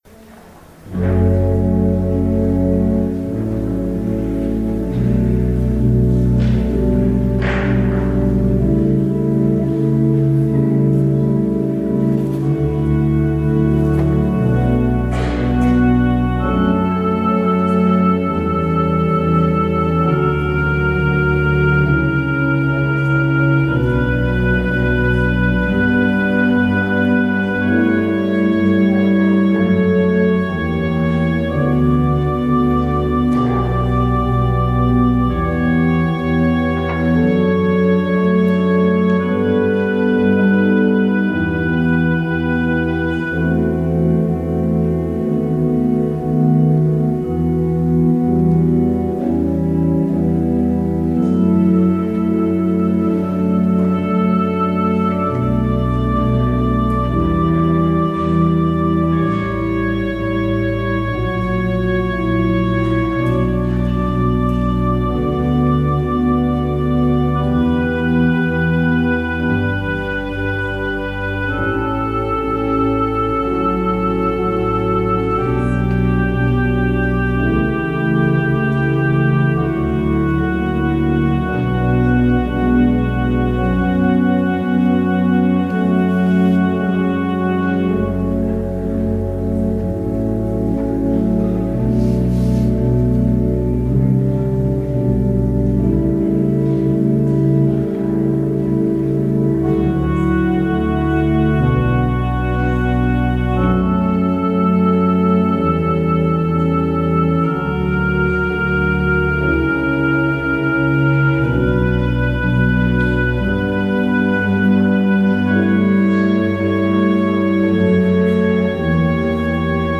Audio recording of the 10am hybrid/streamed service
traditional Hawai’ian chant
Closing hymn